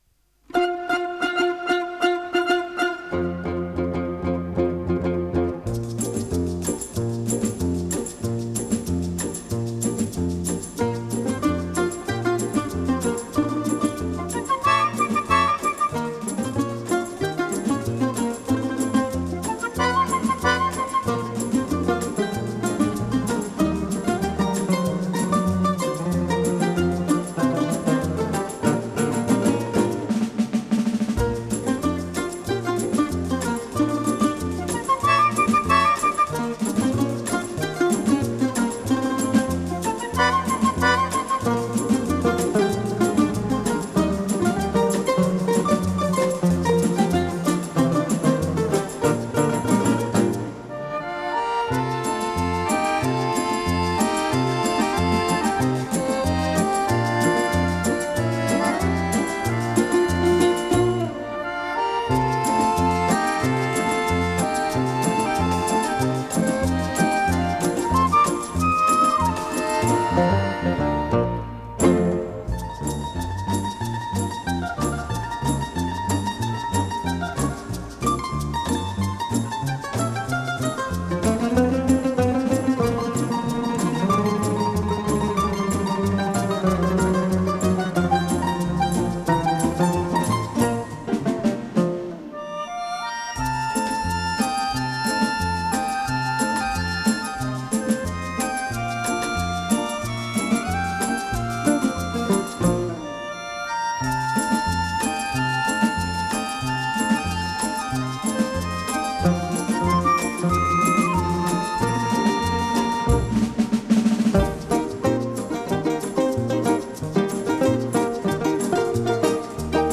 инструментальную версию